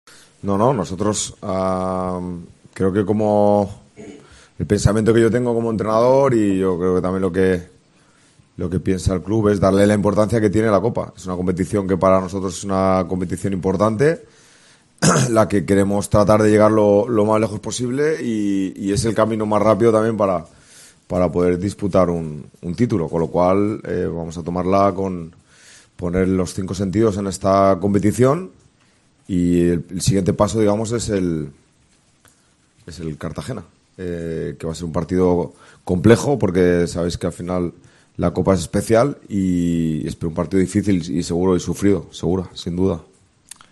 “Mi pensamiento como entrenador y lo que piensa el club es darle importancia a la Copa, es una competición importante, queremos tratar de llegar lo más lejos posible y es el camino más rápido para poder disputar un título. Vamos a poner los cinco sentidos en esta competición, va a ser un partido complejo y sufrido”, dijo Baraja en rueda de prensa.